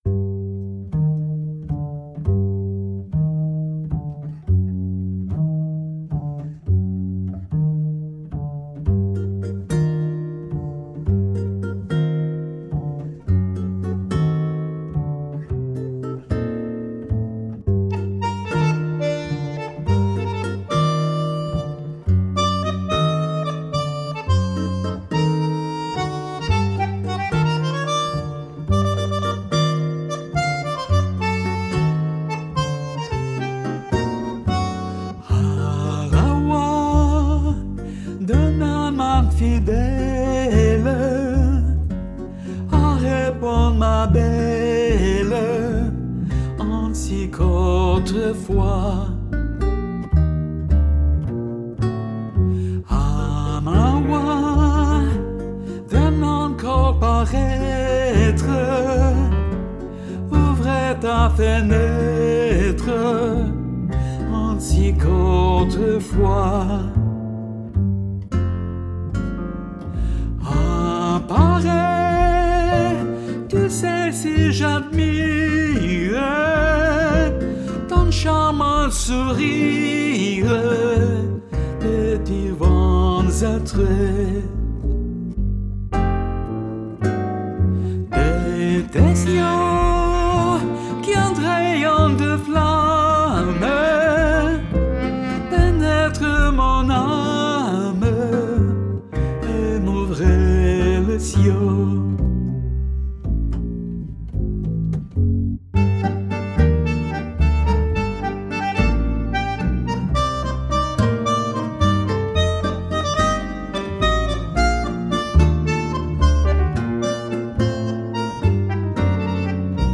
Сейчас свожу проект ф-но, контрабас и барабаны.